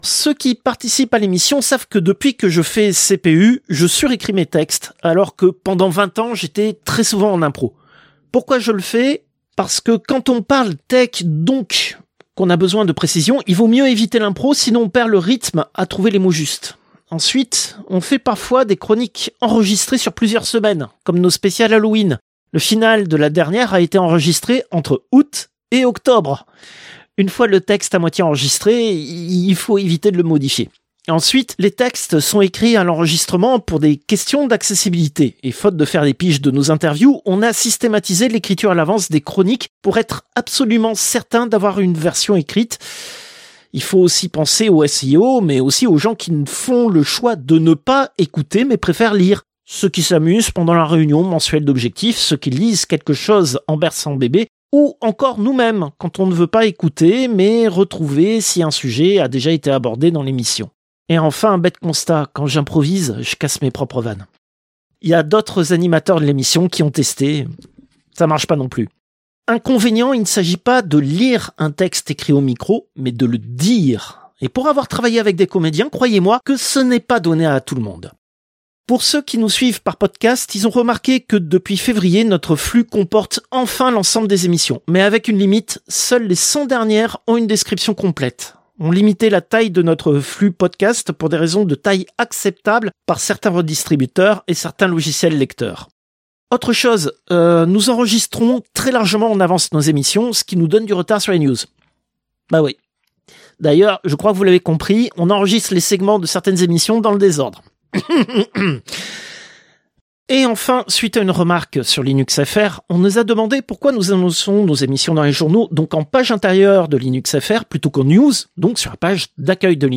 Extrait de l'émission CPU release Ex0241 : lost + found (avril 2026).